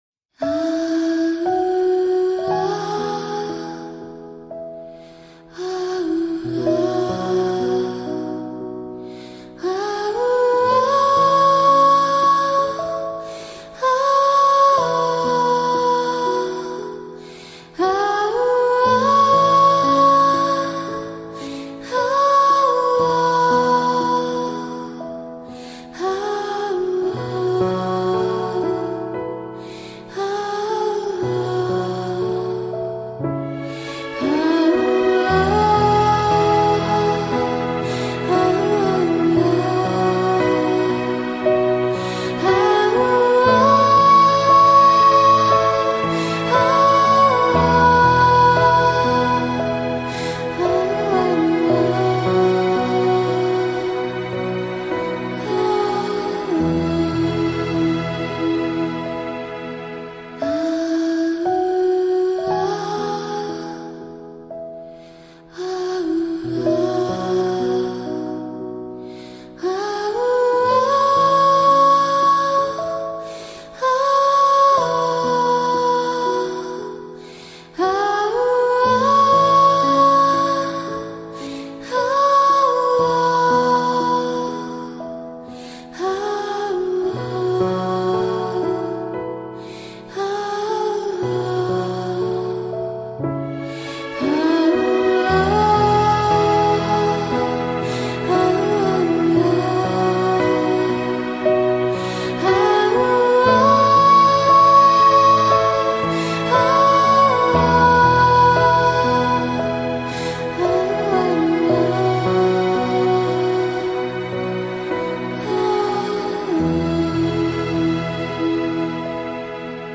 歌声清脆